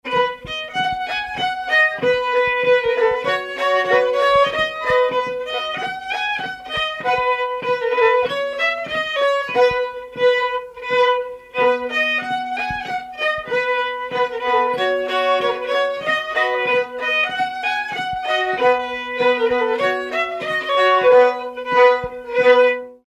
circonstance : carnaval, mardi-gras
Pièce musicale inédite